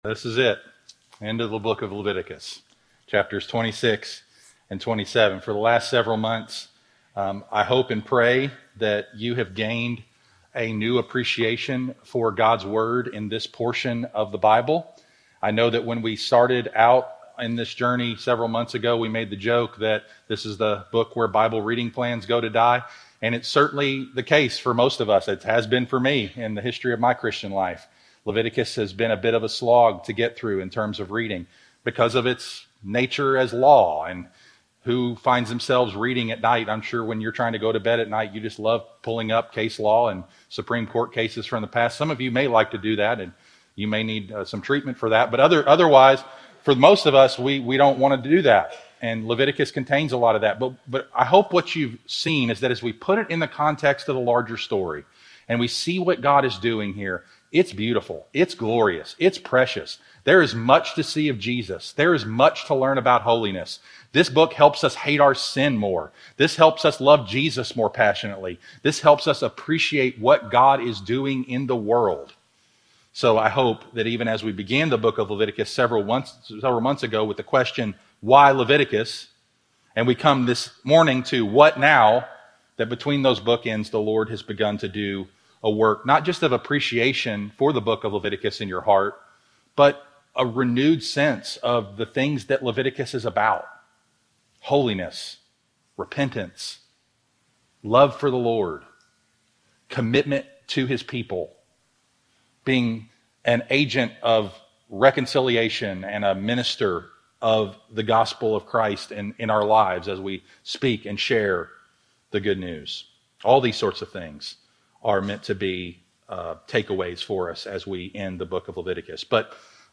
Sermons Podcast - What Will We Do With This Book?